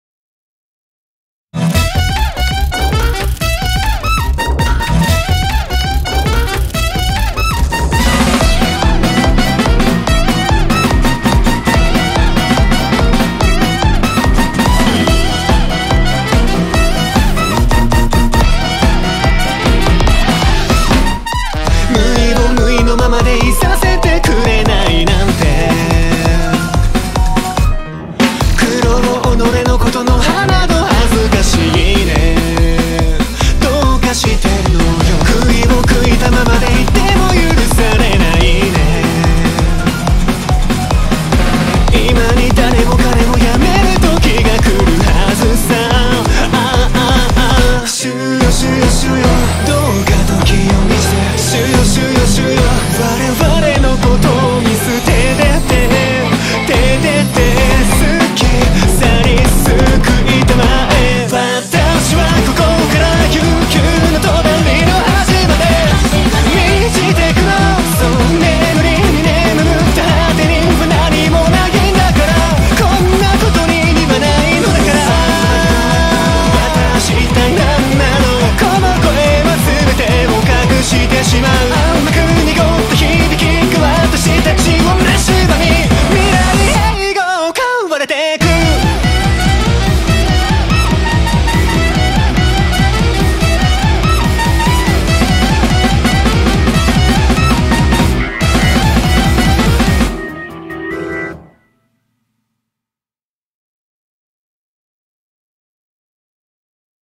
BPM288-288
Audio QualityCut From Video
H - song is 288 bpm (>200)